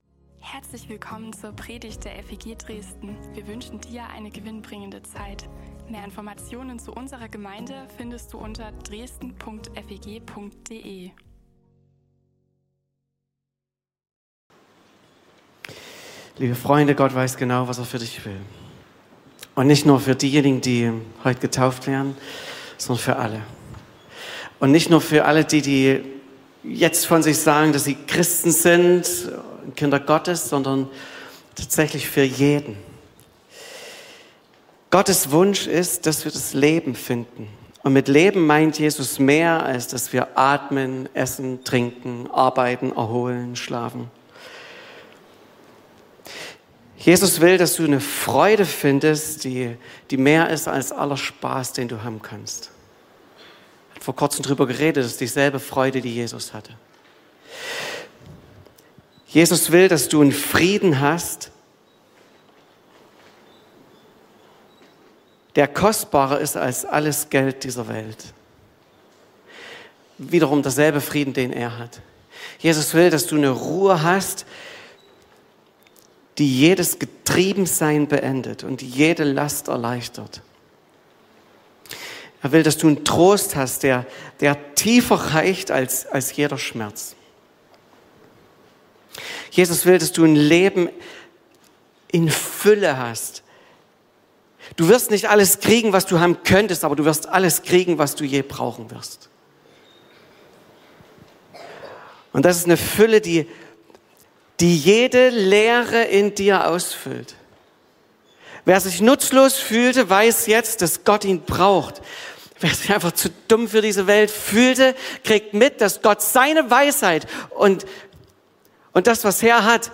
WirEinander - Taufgottesdienst